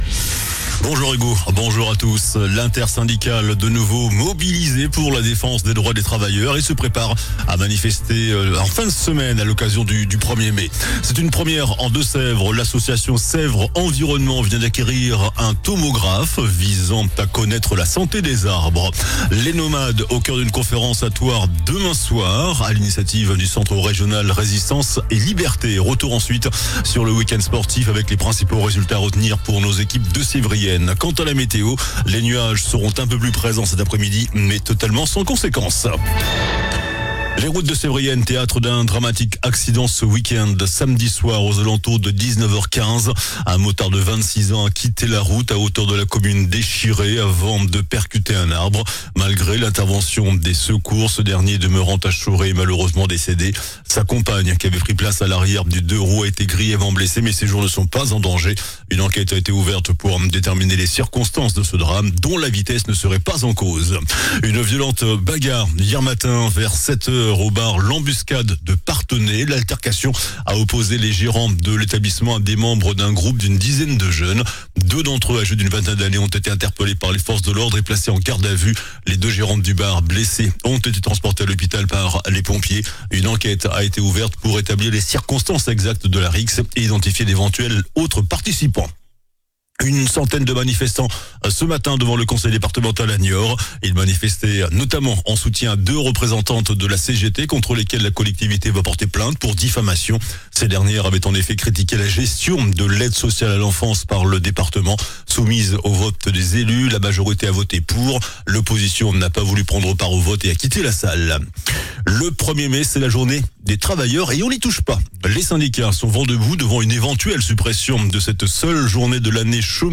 JOURNAL DU LUNDI 27 AVRIL ( MIDI )